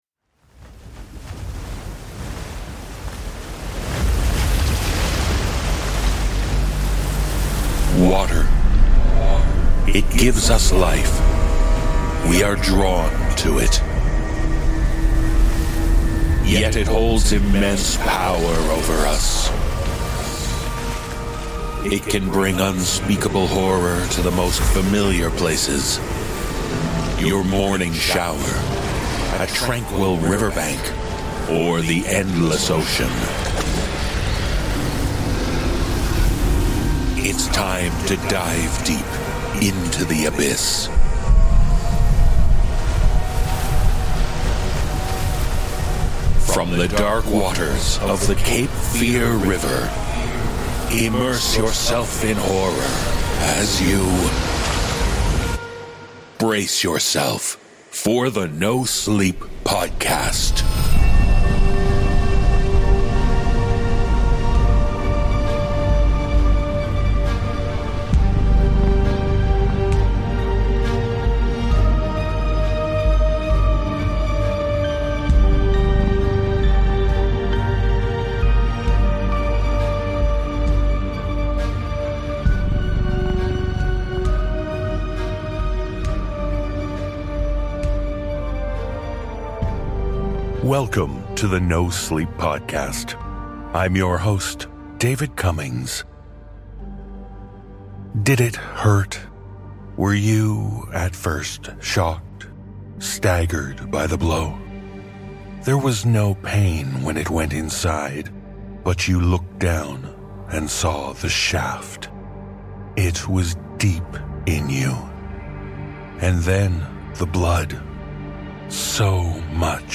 Cast: Narrator
Musical score
No generative AI is used in any aspect of work.